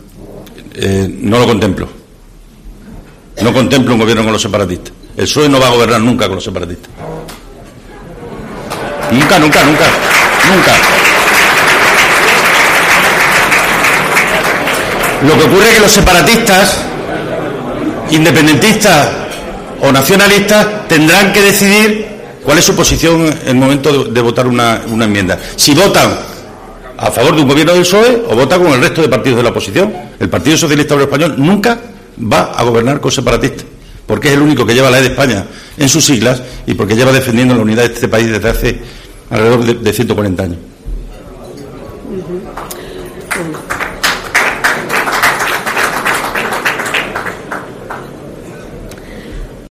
El presidente extremeño ha recibido aplausos al hacer esa afirmación, que se han apagado casi del todo al explicar los pormenores de su profecía.